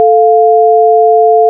是的，我们听到声音合并为一个和弦。
Audio(y+y2, rate=fs)                            # Play both sounds together
如果您想知道为什么这听起来就像手机上的按键音，那是因为手机按键会播放两个纯音，作为声音来识别您按下的按钮。